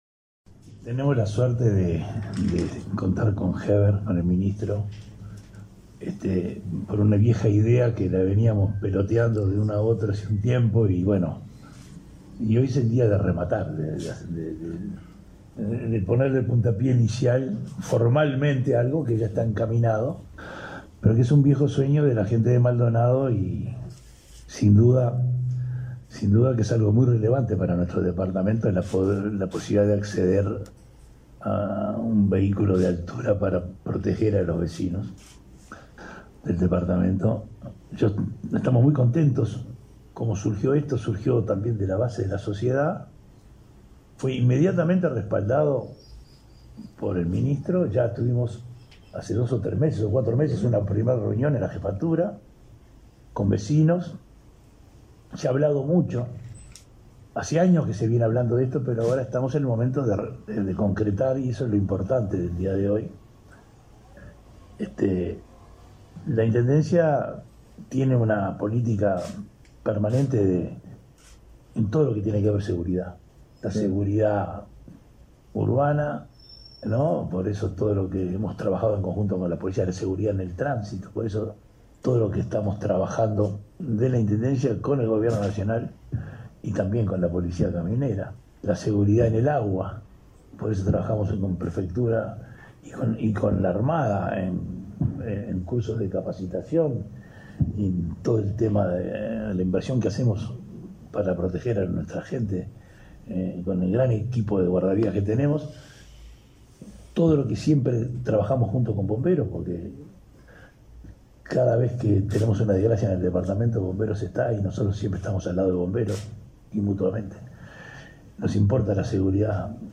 Conferencia de prensa por adquisición de maquinaria para combatir incendios en Maldonado